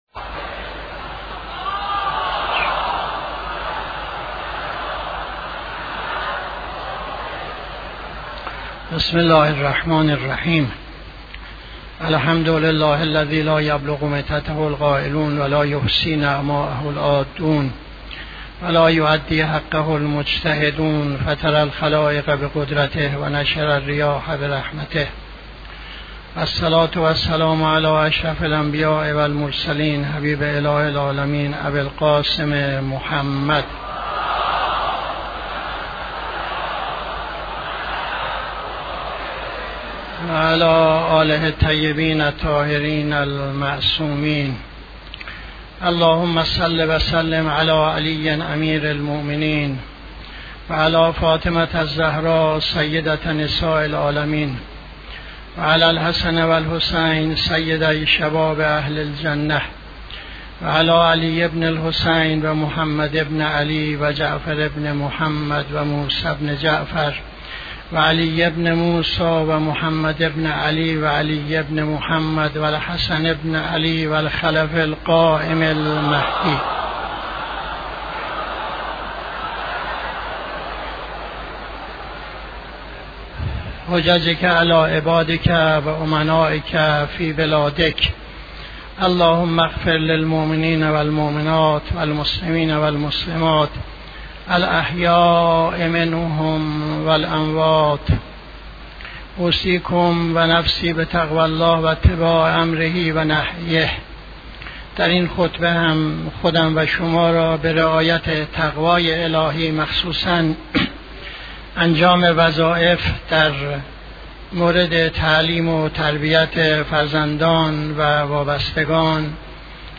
خطبه دوم نماز جمعه 29-04-80